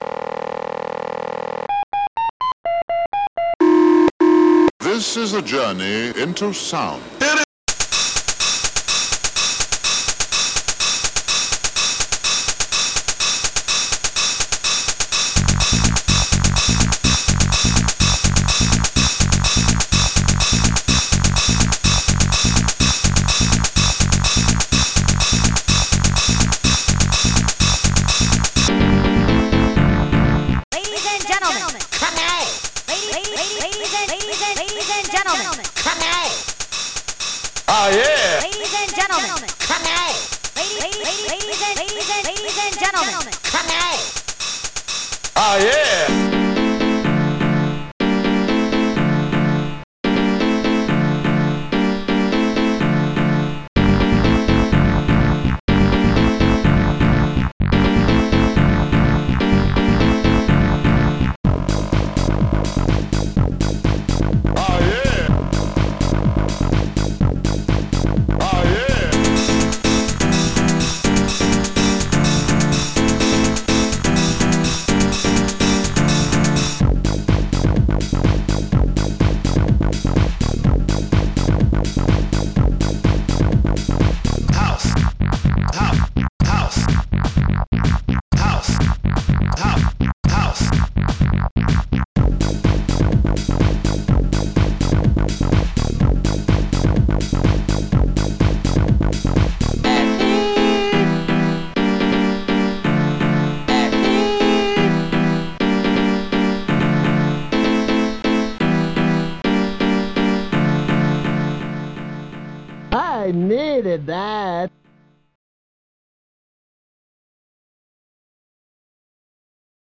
st-66:akaiopenhh
st-66:RubberBass
st-66:klavier
st-12:pumpbassdrum0